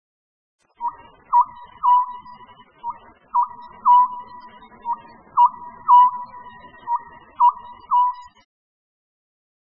〔コノハズク〕ブッポーソー（ブッキョウブッキョウ）／薄暗い針葉樹林などで見られ
konohazuku.mp3